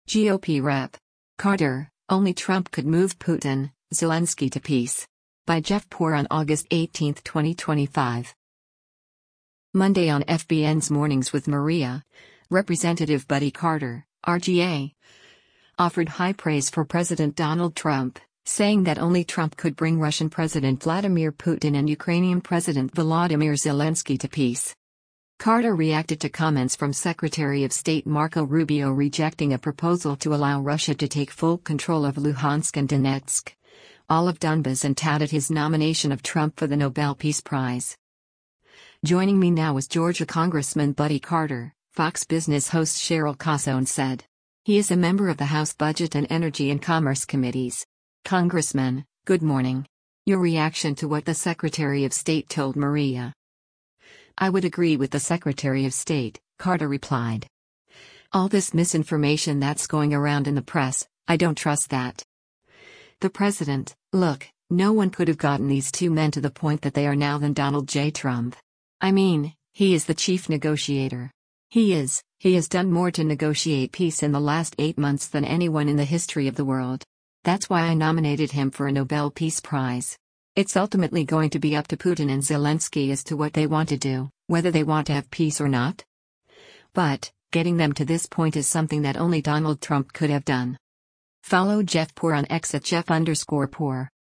Monday on FBN’s “Mornings with Maria,” Rep. Buddy Carter (R-GA) offered high praise for President Donald Trump, saying that only Trump could bring Russian President Vladimir Putin and Ukrainian President Volodymyr Zelensky to peace.